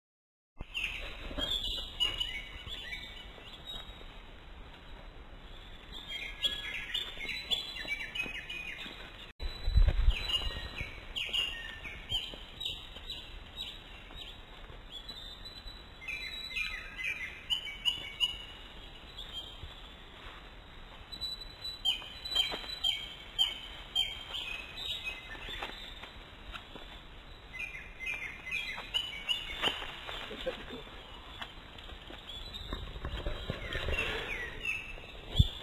ツ　グ　ミ　属   クロツグミ １　1-05-20
鳴 き 声：キョッキョキョキョキョと鋭く鳴き、ツィーという声も出す。高い枝にとまり、朗らかな声で、キョロイ、キョロイ、キョコキョコ、
コケエコ。ケコ、コキーヨと長く囀る。
鳴き声１
kurotugumi01.mp3